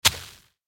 دانلود آهنگ آب 16 از افکت صوتی طبیعت و محیط
دانلود صدای آب 16 از ساعد نیوز با لینک مستقیم و کیفیت بالا
جلوه های صوتی